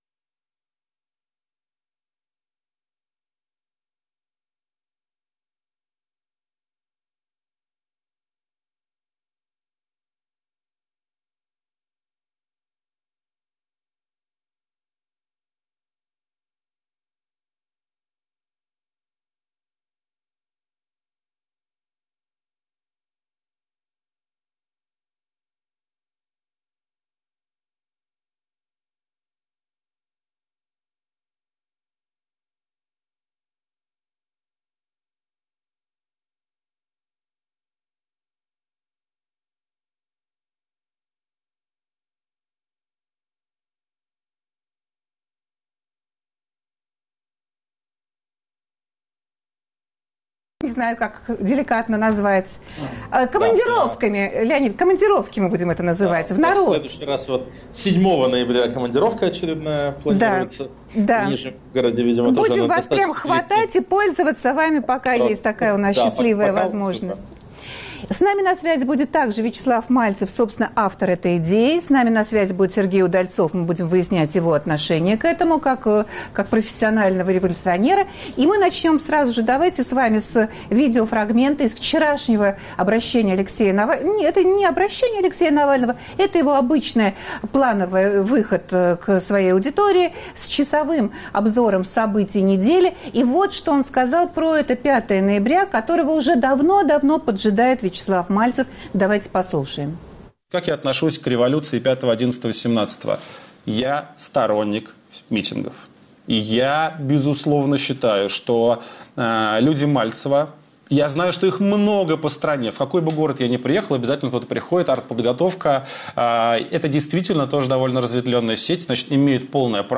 05.11.17 Может ли в России 5 ноября 2017-го года случиться революция, которую давно предсказывает политик Вячеслав Мальцев? Если да, то к чему она приведет? В разговоре участвуют